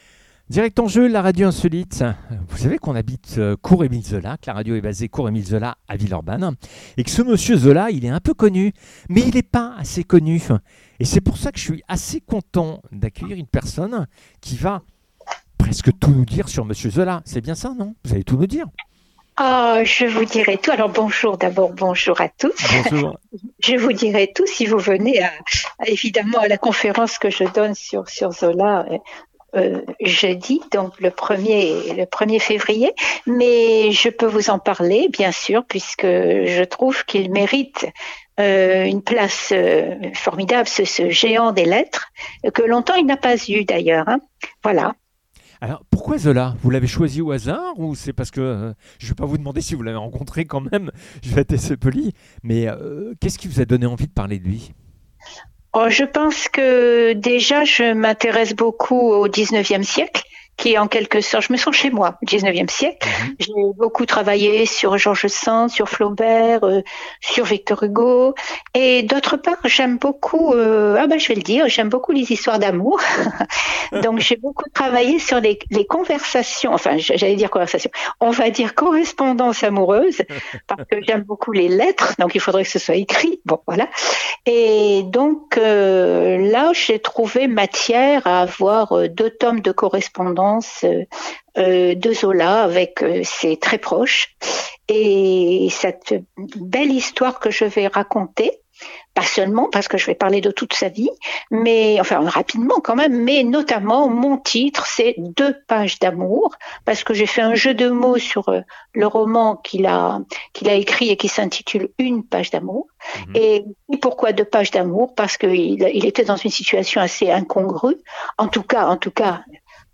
conférence Emile Zola